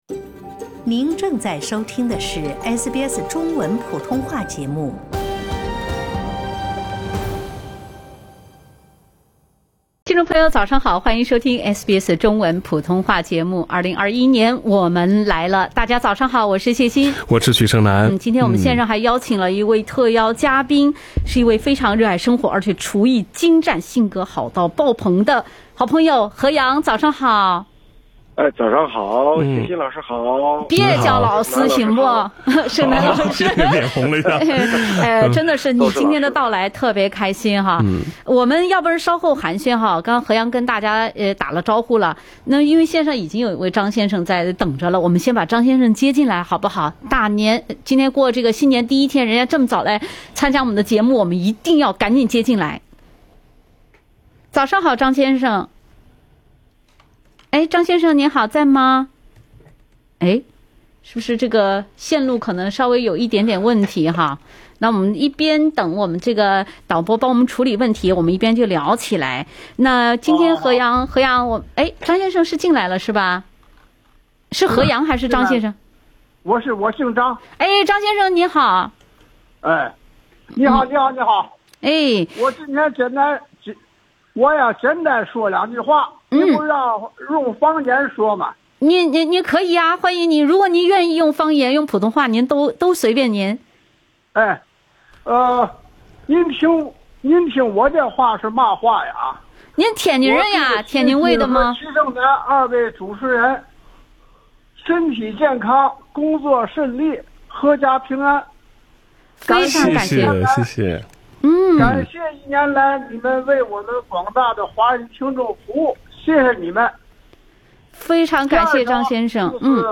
元旦熱線-方言送上新年祝福